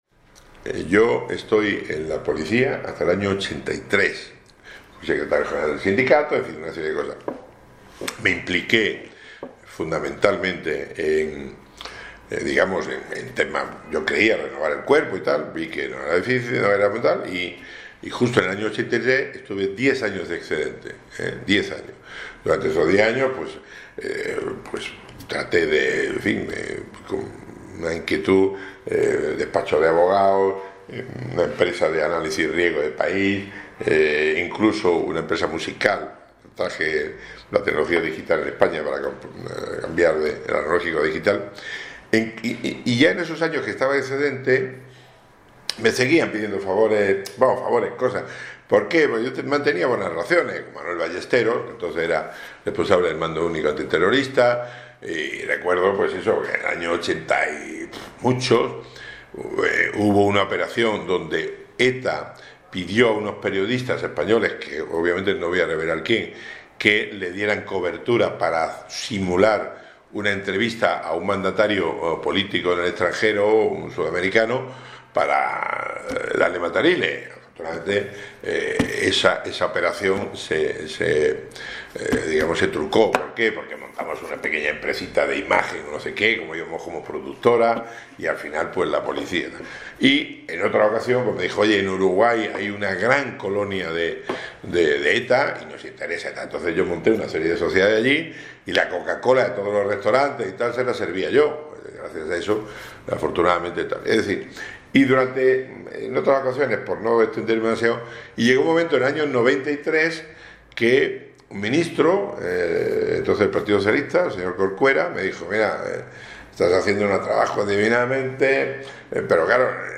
Declaraciones del excomisario Villarejo